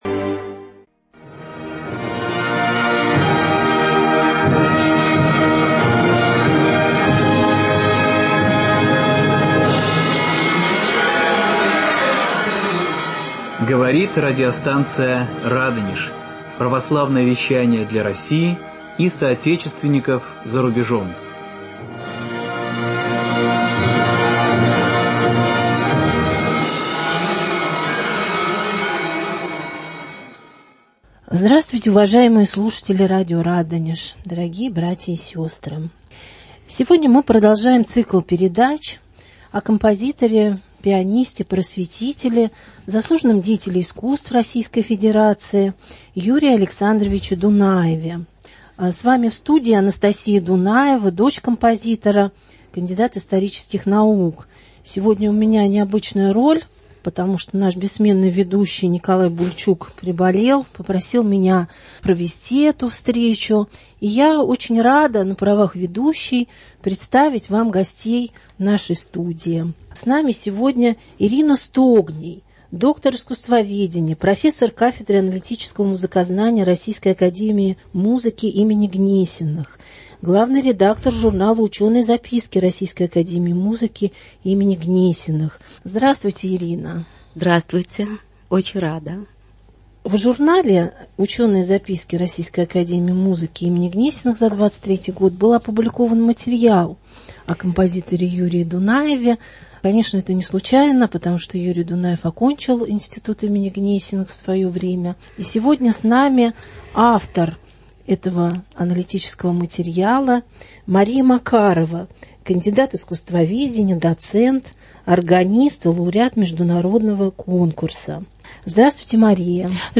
И, конечно, слушаем неповторимую, как наступающая весна, музыку композитора: фортепианную сюиту «Акварели», фортепианный цикл «Четыре прелюдии», Вариации для струнного оркестра, Симфониетту, Цикл романсов «Край любимый» на стихи Сергея Есенина.